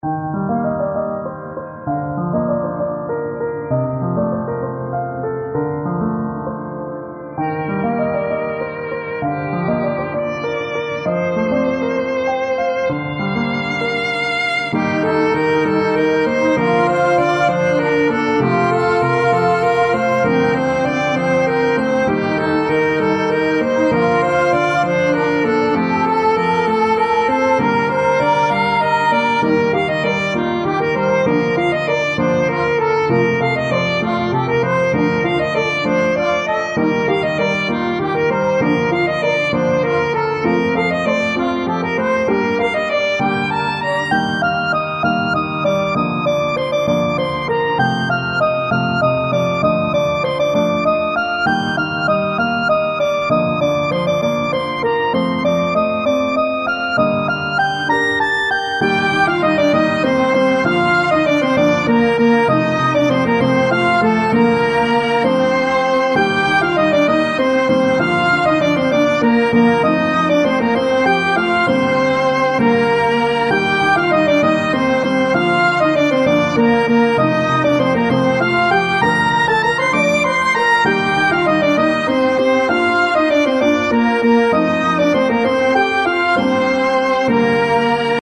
それぞれ１ループの音源です♪
イントロあり